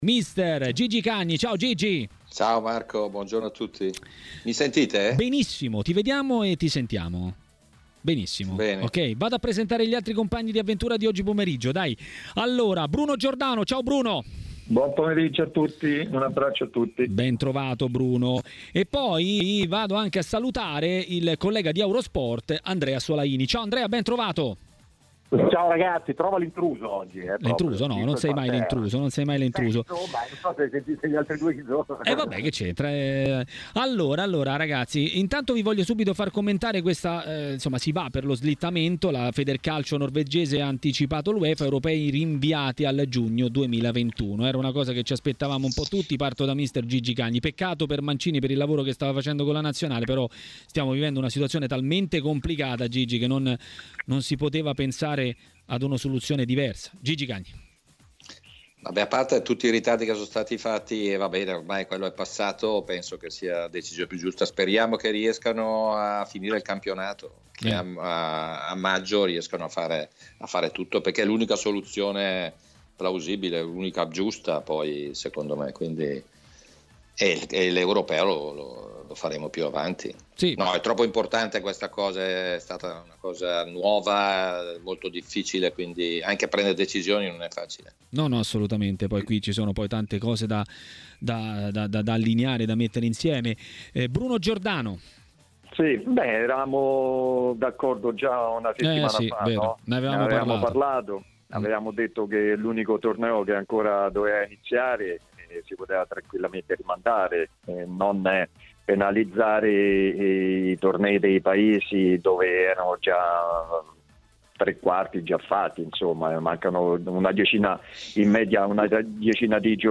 Per dire la sua sui temi del momento è intervenuto in diretta a Maracanà, nel pomeriggio di TMW Radio, il tecnico Gigi Cagni.